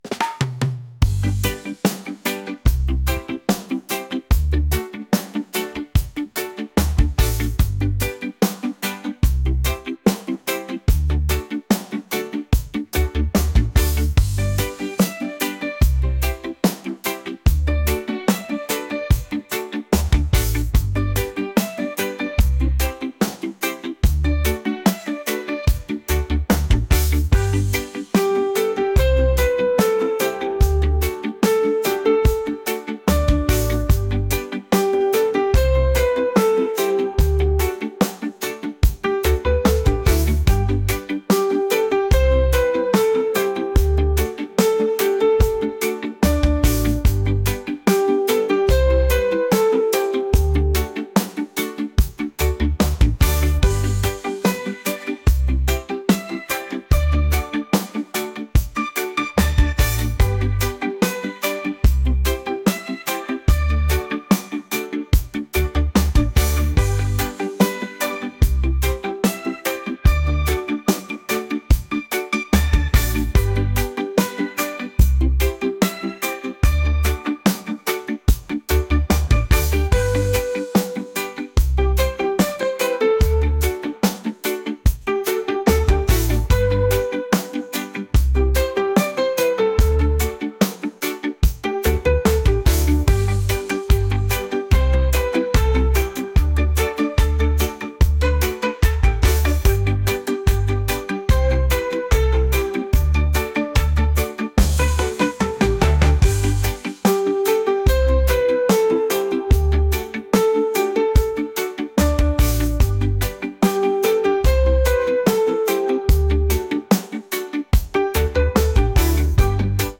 reggae | pop | lounge